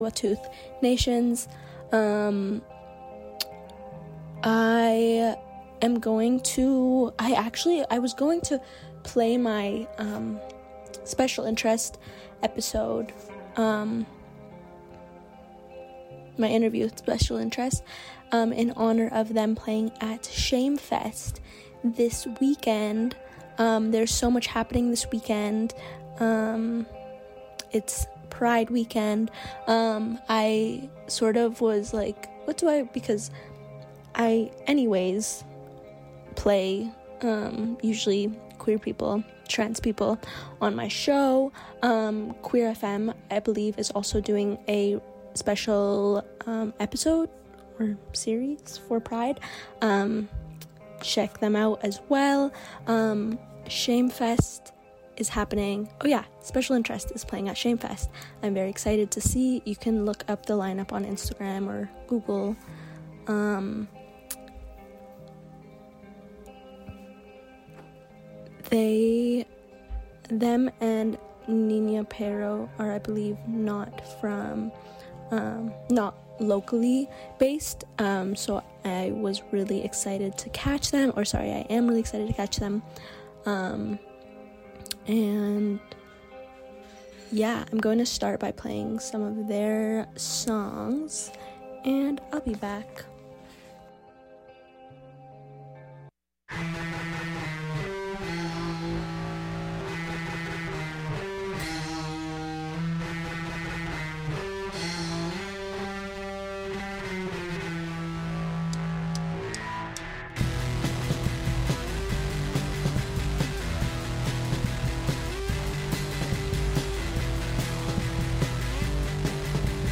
This episode I mainly play music and read from interviews published in Discorders issue published September of 1988, they are both by lesbians who performed in Vancouver's folk festival.